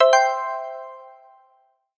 popup-sound.mp3